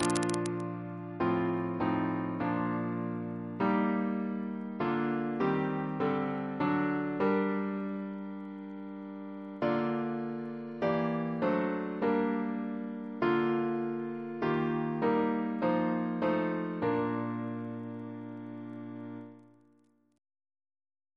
Double chant in B♭ Composer